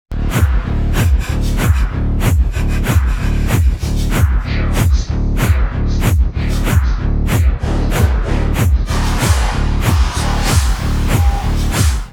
techno-15-pv.wav